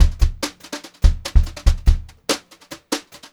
144GVBEAT3-R.wav